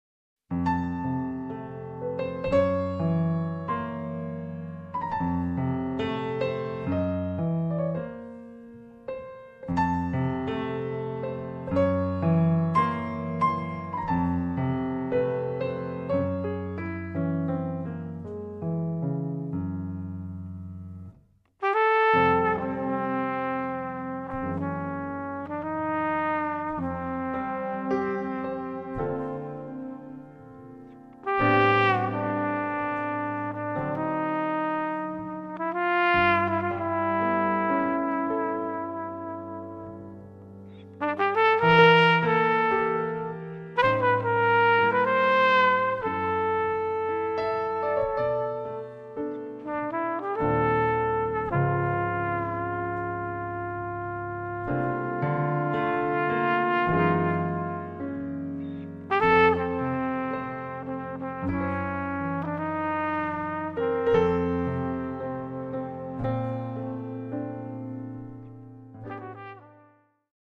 tromba e flicorno
sax tenore, alto e soprano
pianoforte
basso elettrico
batteria
ballad melodica e malinconica